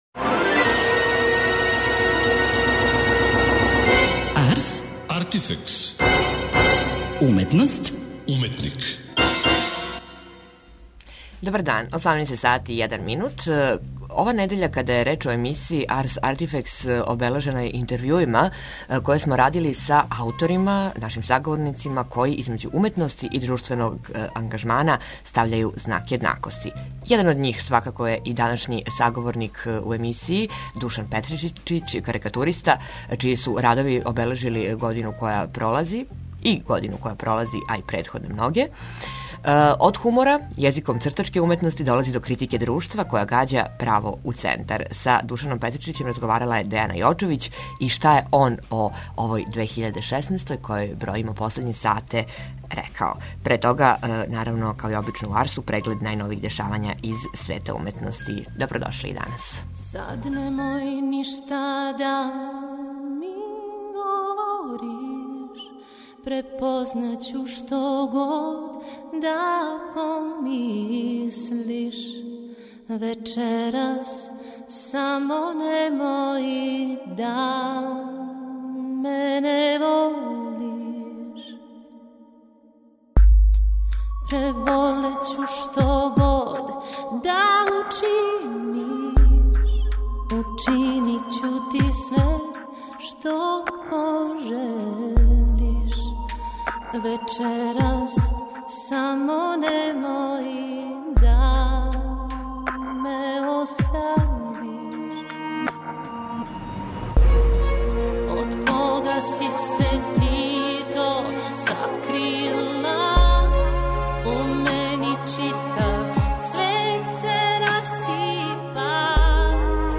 Предновогодишња недеља биће у знаку разговора са ауторима који између уметности и друштвеног ангажмана стављају знак једнакости.